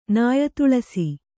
♪ nāya tuḷasi